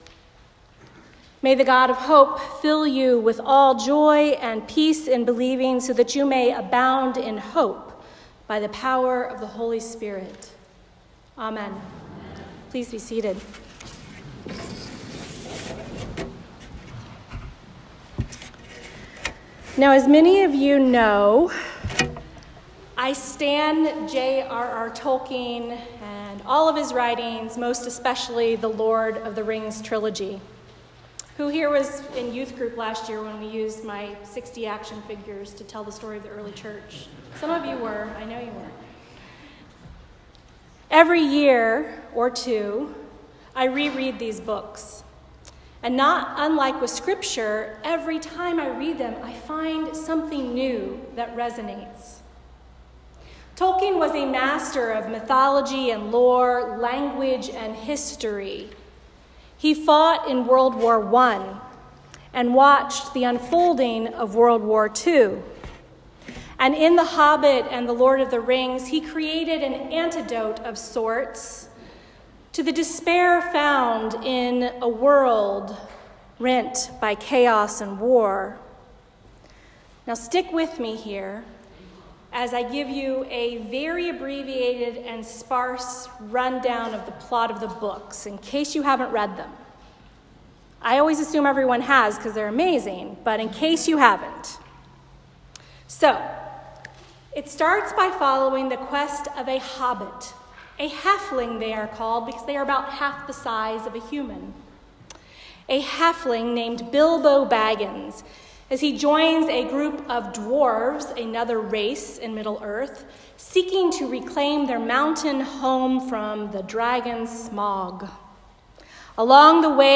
A Sermon for the Second Sunday of Advent